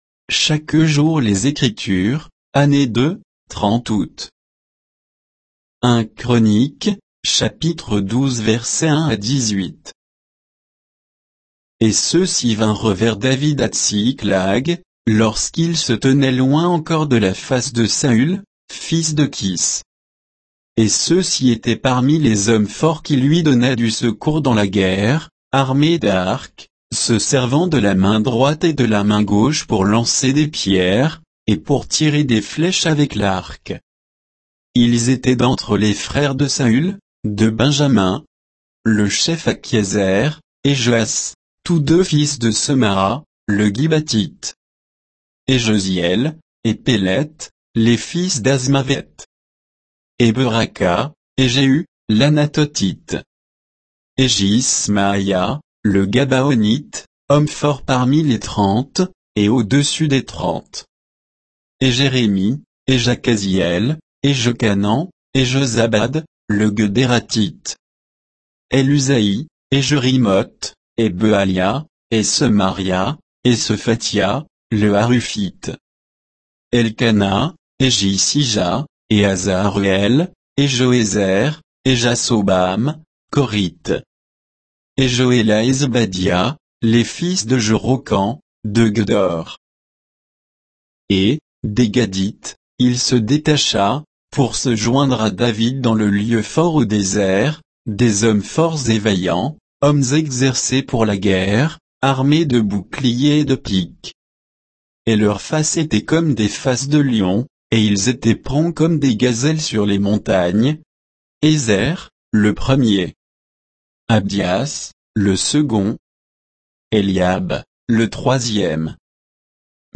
Méditation quoditienne de Chaque jour les Écritures sur 1 Chroniques 12